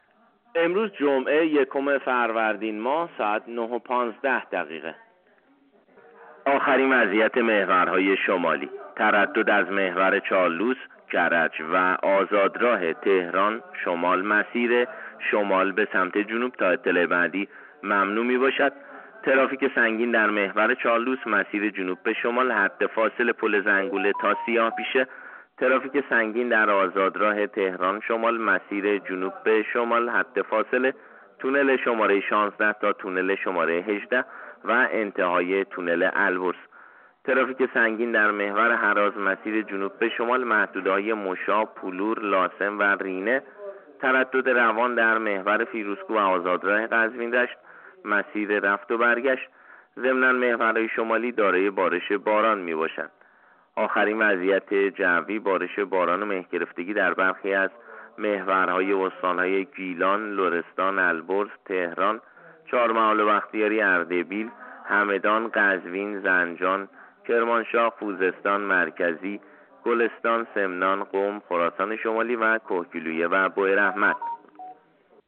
گزارش رادیو اینترنتی از آخرین وضعیت ترافیکی جاده‌ها ساعت ۹:۱۵ اول فروردین؛